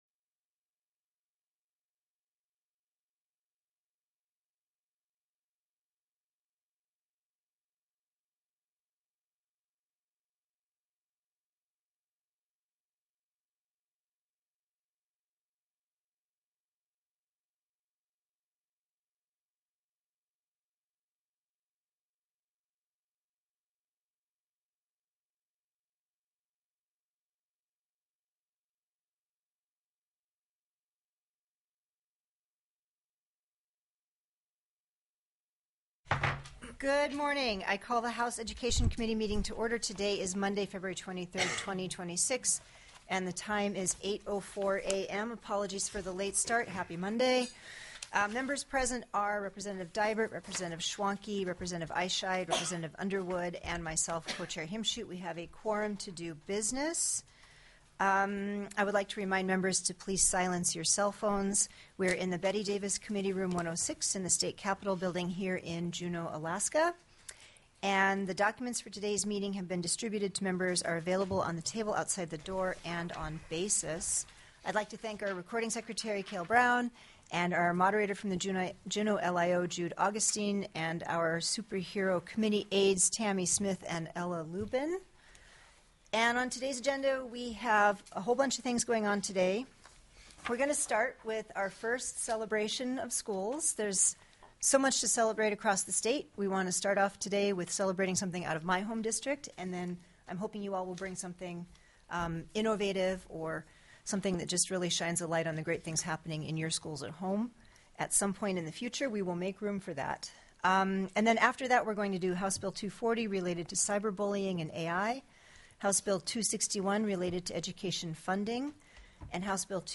The audio recordings are captured by our records offices as the official record of the meeting and will have more accurate timestamps.
-- Invited & Public Testimony -- *+ HB 246 SPECIAL EDUCATION SERVICE AGENCY FUNDING